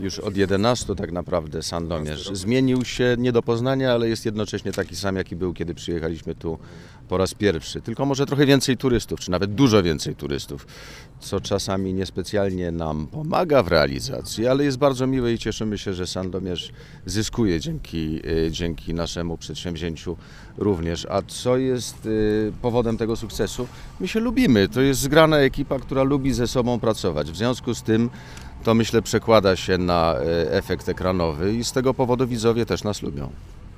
Skąd popularność serialu i jak zmienił się Sandomierz od czasu pierwszych zdjęć do „Ojca Mateusza”, mówi Artur Żmijewski wcielający się w tytułową postać: